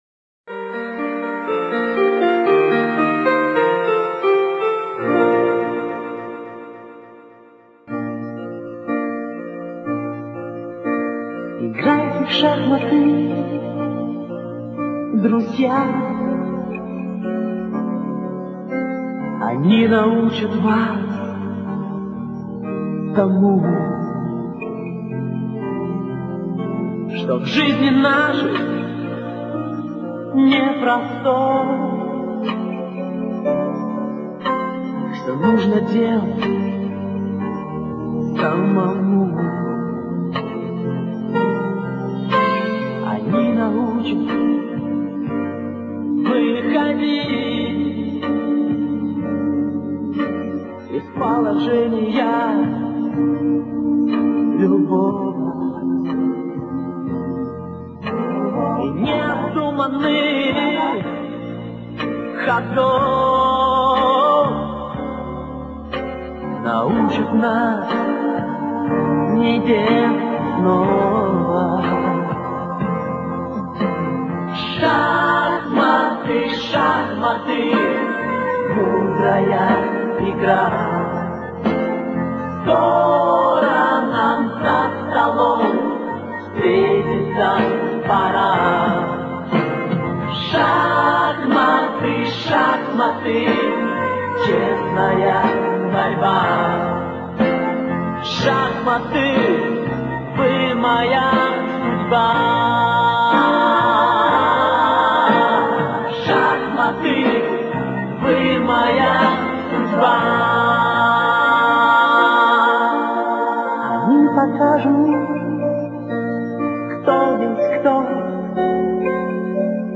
достойного качества (аудио)